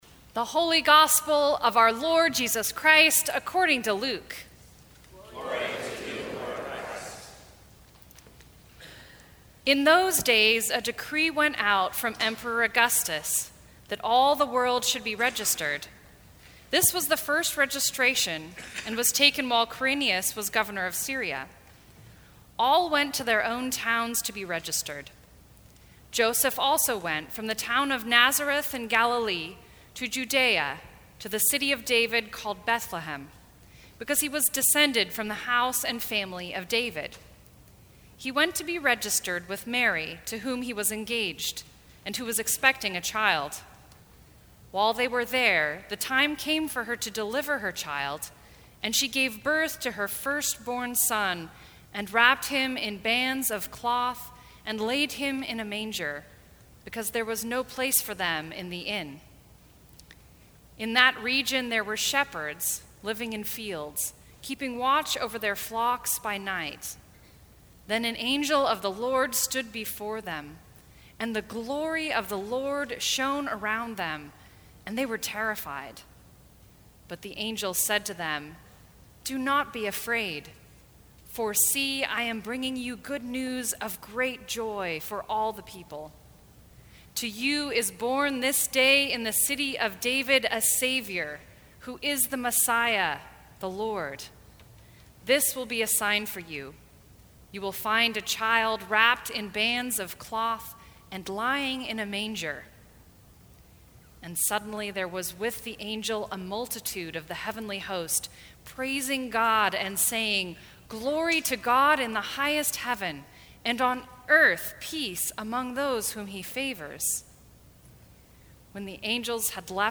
Christmas Eve
Sermons from St. Cross Episcopal Church Sharing the Gift Dec 27 2017 | 00:18:14 1x 00:00 / 00:18:14 Subscribe Share Apple Podcasts Spotify Overcast RSS Feed Share Link Embed